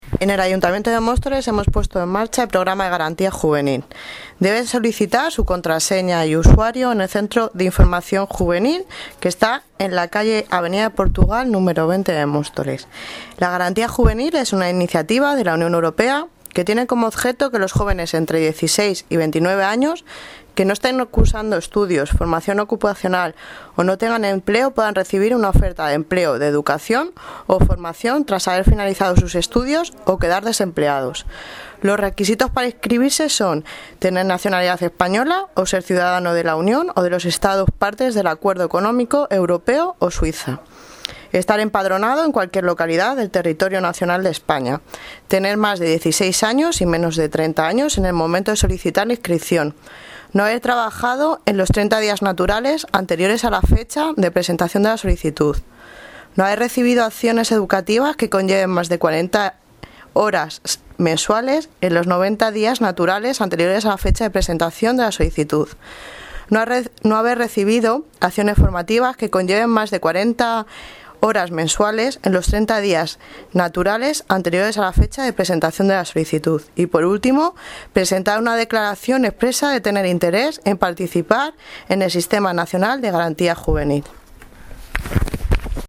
Audio - Arantxa Fernández (Concejala de Educación y Juventud) sobre Sistema garantía de empleo